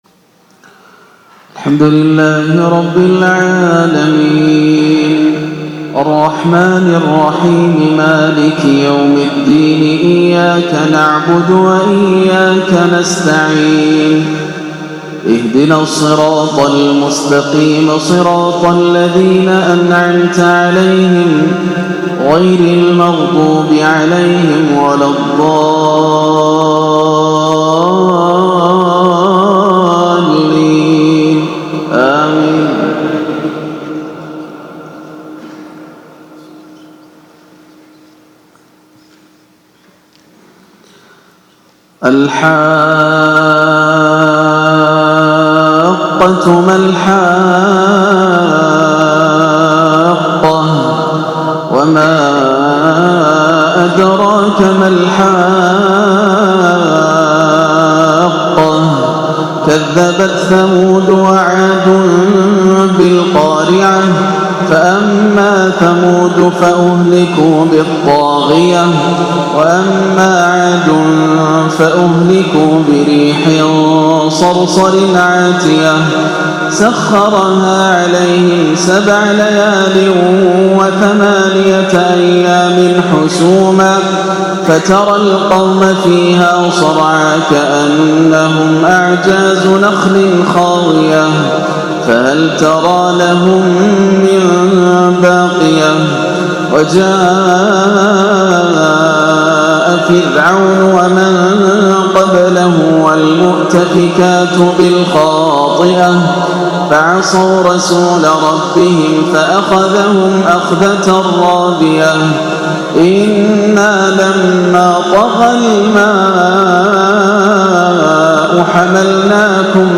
عشاء الثلاثاء 1-4-1439هـ سورة الحاقة > عام 1439 > الفروض - تلاوات ياسر الدوسري